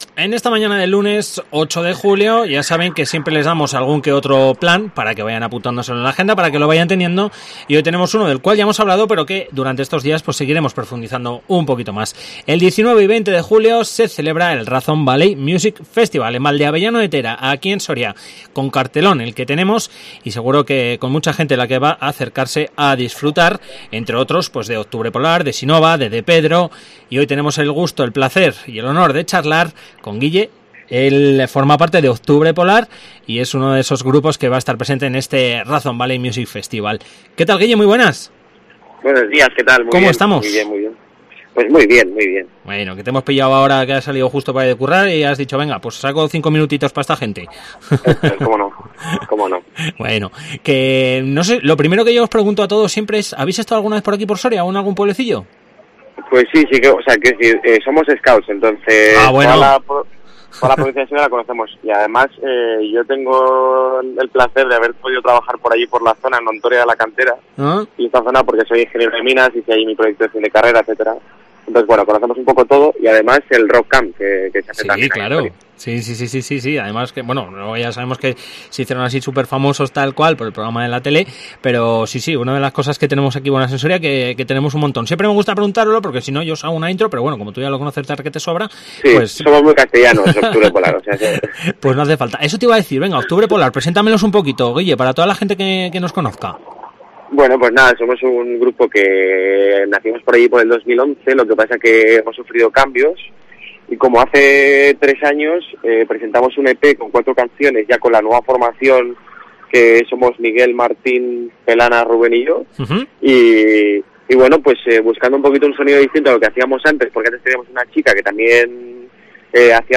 ENTREVISTA Octubre Polar Festival Razon Valley